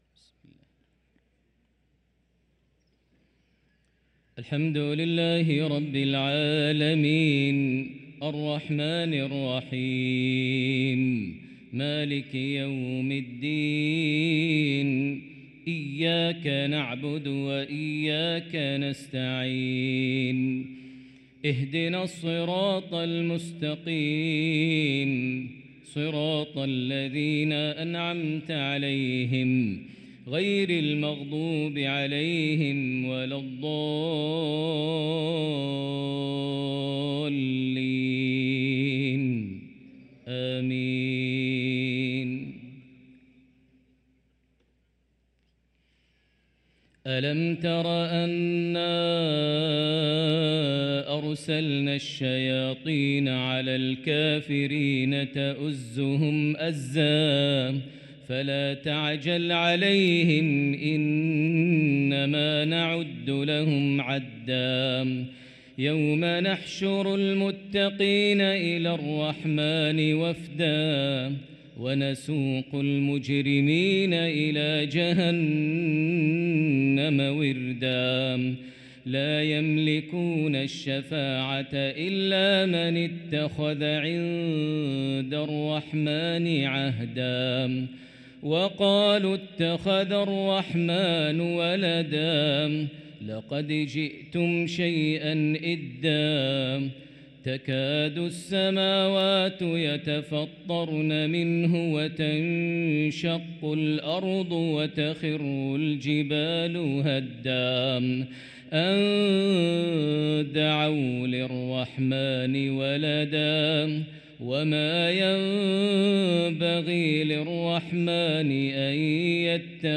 صلاة المغرب للقارئ ماهر المعيقلي 23 صفر 1445 هـ
تِلَاوَات الْحَرَمَيْن .